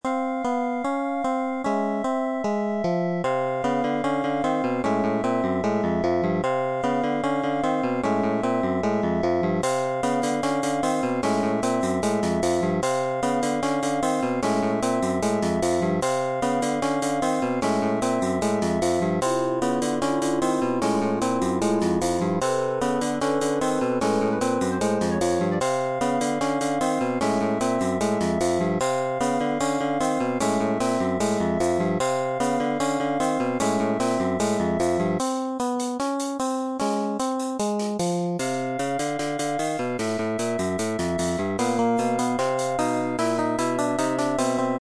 Download Suspence sound effect for free.
Suspence